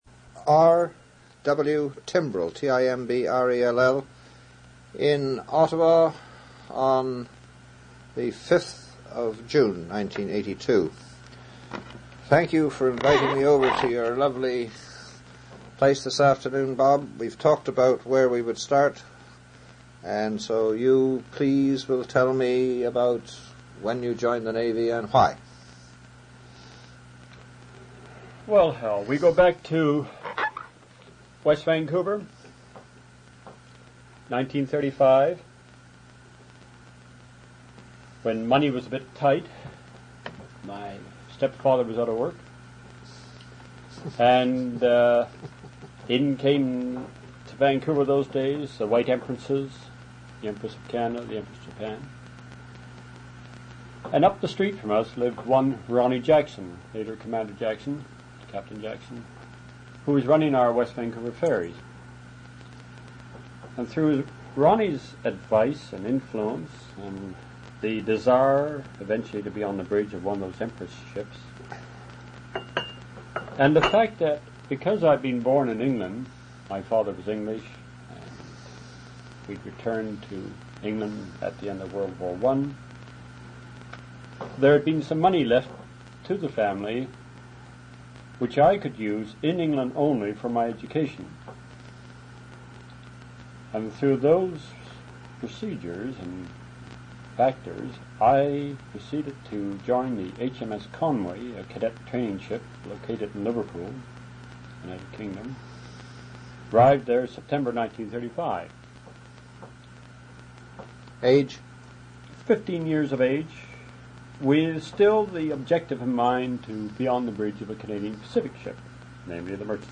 An interview/narrative of Robert W. Timbrell's experiences during World War II. Rear Admiral Timbrell served with the Royal Canadian Navy.